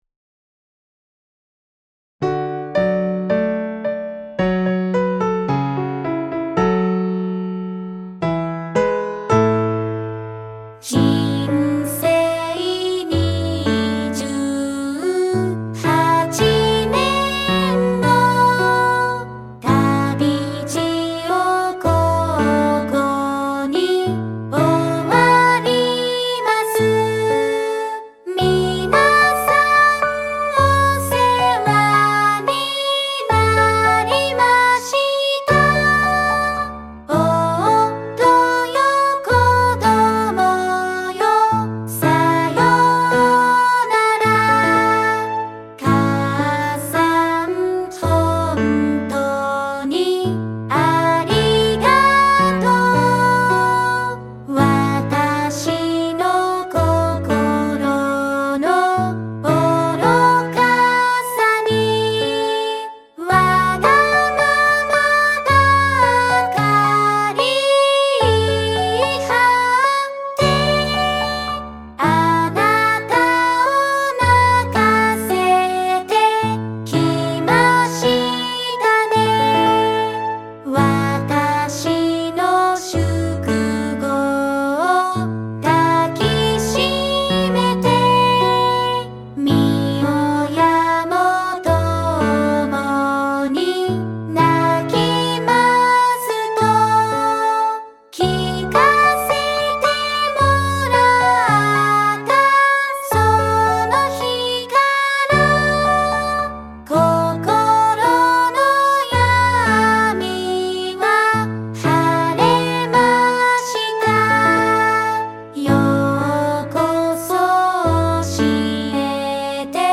唄：巡音ルカ・GUMI